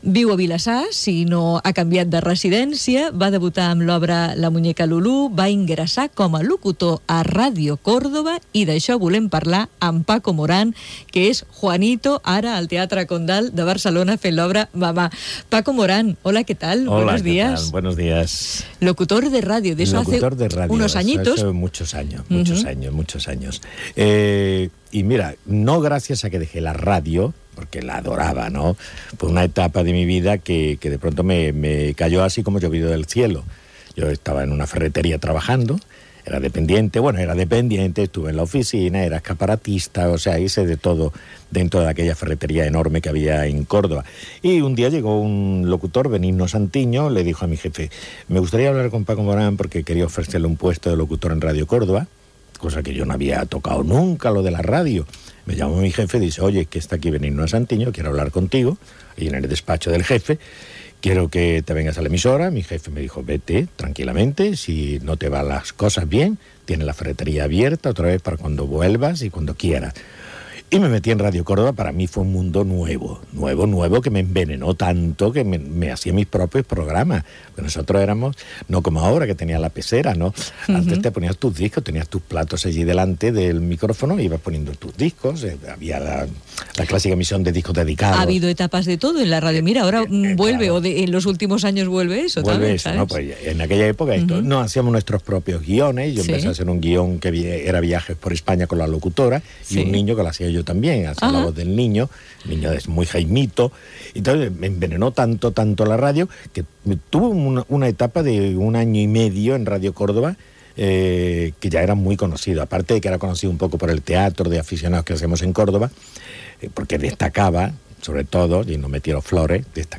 Entrevista a l'actor Paco Morán que parla que va fer de locutor a Radio Córdoba
Divulgació